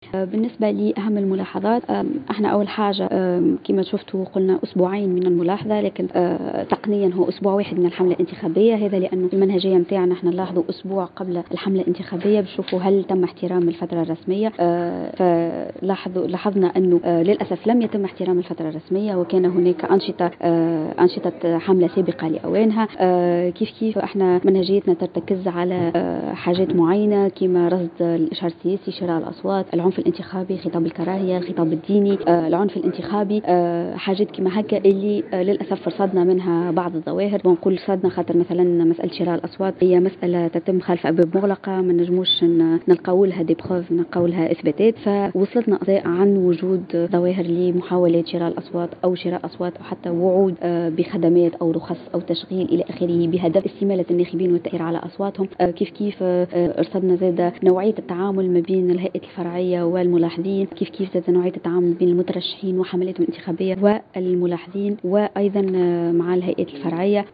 وأضافت في تصريح اليوم لمراسل "الجوهرة أف أم" على هامش ندوة صحفية عقدها أحد المترشحين للانتخابات الرئاسية، أن هذه التجاوزات تهم القيام بحملات سابقة لأوانها ووجود محاولات لشراء أصوات الناخبين واستمالتهم وكذلك خطاب الكراهية.